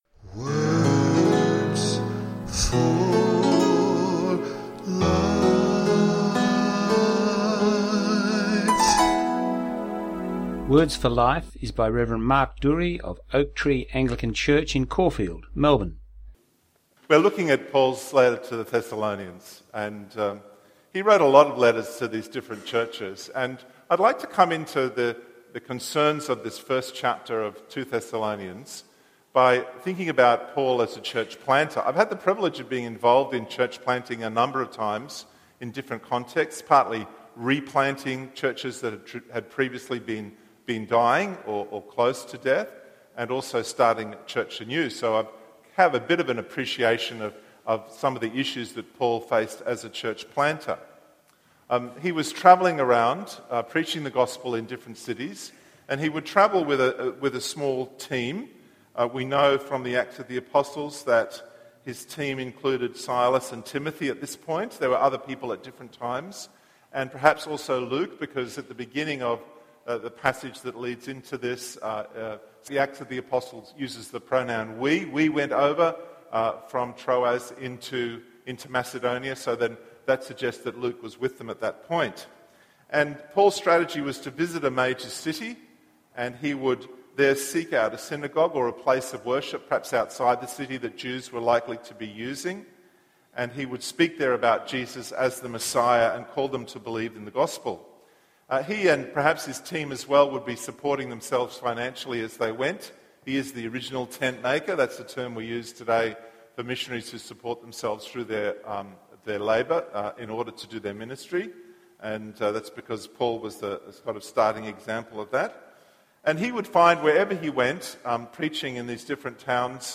His talk title was “Coming judgement”.